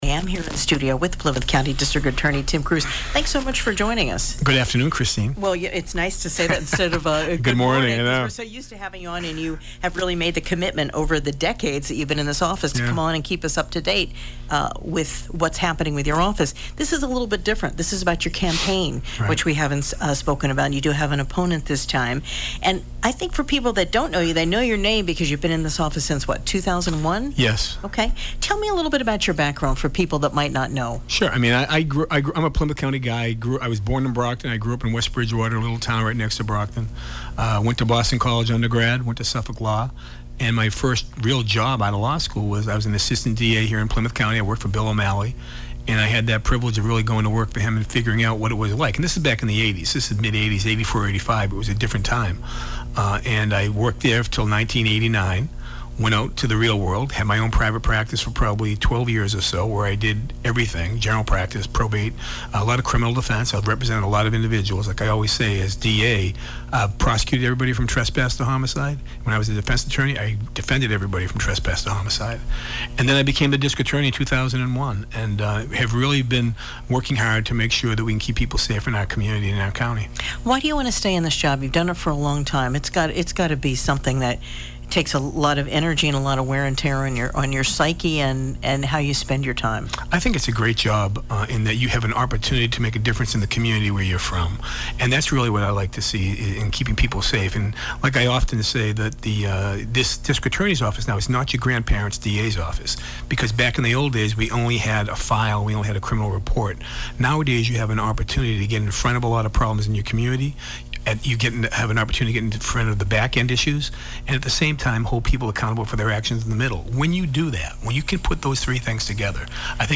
Plymouth County District Attorney Tim Cruz speaks